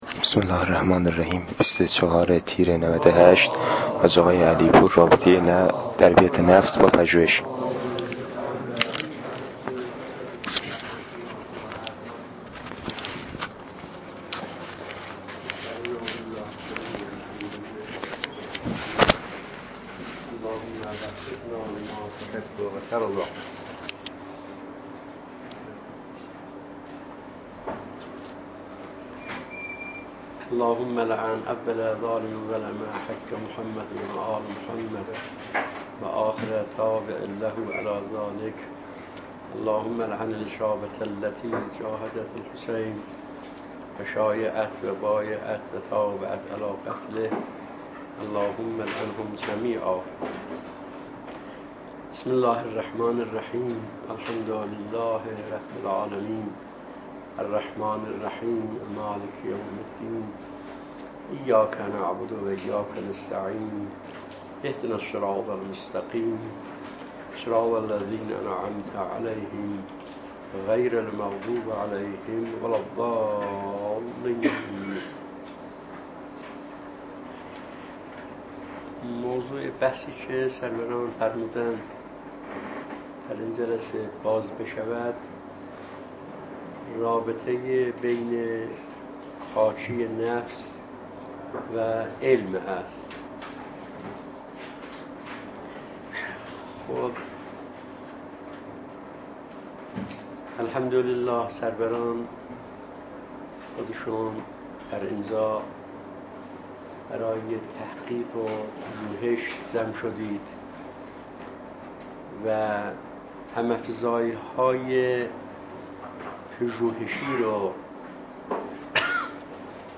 رابطه تهذیب نفس و تحصیل علم ✍ سخنرانی در جمع طلاب